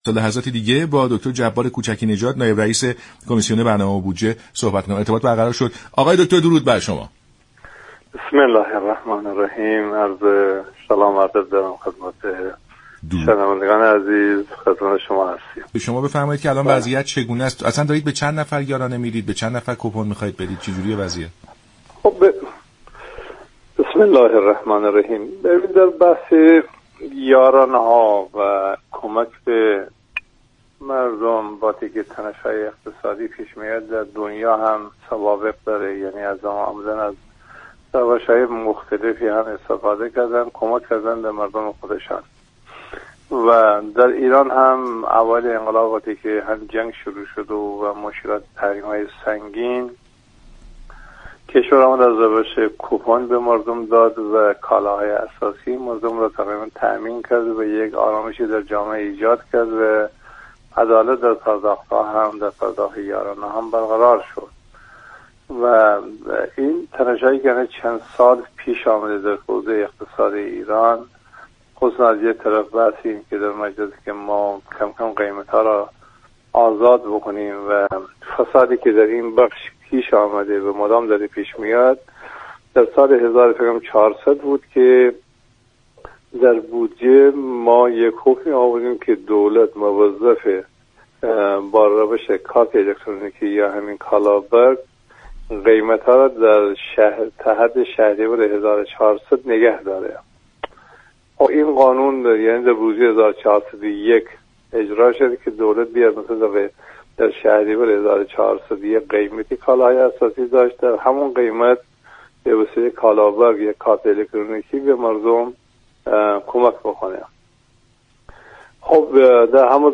نائب رییس كمیسیون برنامه‌و‌بودجه در برنامه سلام‌صبح‌بخیر گفت: در اوائل جنگ 8 ساله علیه ایران، دولت با ارائه كوپن كالاهای مردم را تامین‌كرد.